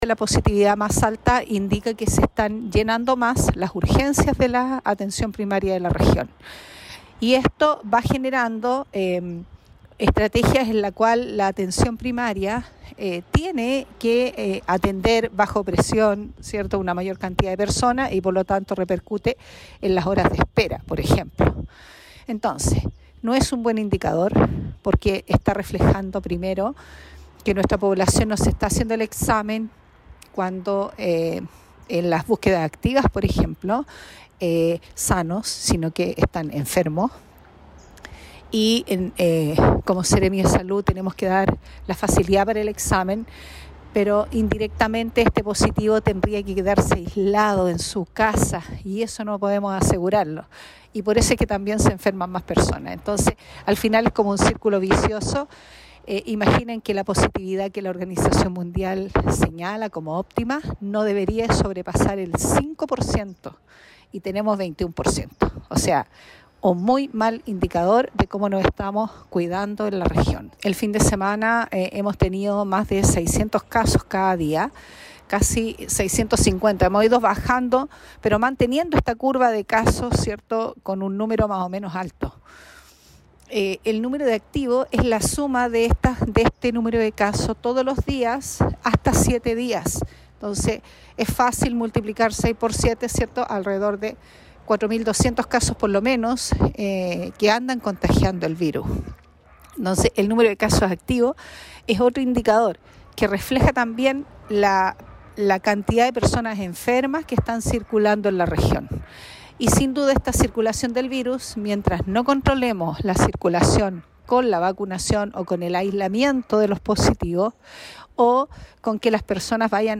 En un nuevo reporte sanitario, la Seremi de Salud, Paola Salas, explicó la realidad epidemiológica que vive actualmente la región de Coquimbo.